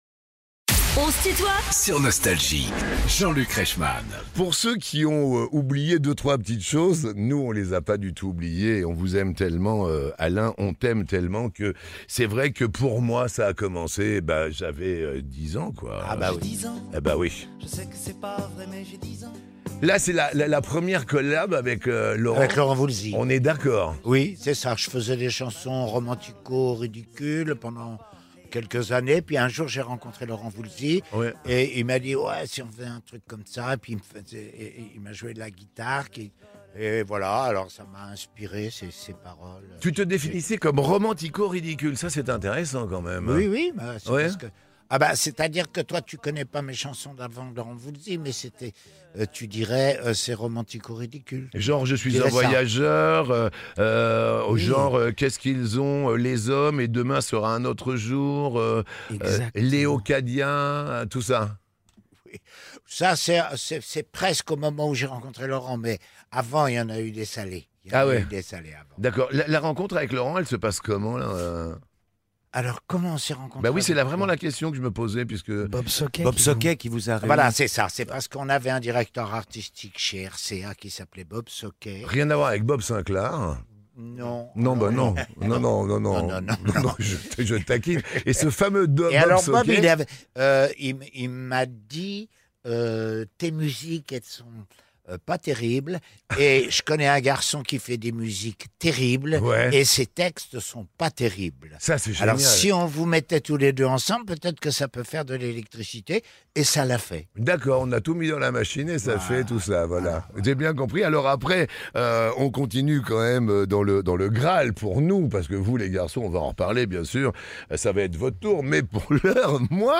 Jean-Luc Reichmann présente Alain Souchon et ses fils dans son émission "On se tutoie ?..." sur Nostalgie ~ Les interviews Podcast
Deux frères, deux voix, un père en héritage ! Alain Souchon et ses fils sont les invités de "On se tutoie ?..." avec Jean-Luc Reichmann